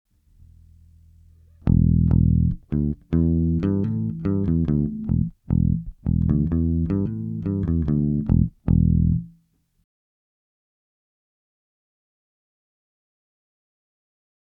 Aber tatsächlich habe ich heute meine XLR-Kabel und eine DI-Box geholt um den balanced Input mal noch auszuprobieren und beim Line-Out hat sich das Problem anscheinend in Luft aufgelöst... (siehe Anhänge)
• 5 Bass Input - Line Out, Gain 50%, Master 20%.mp3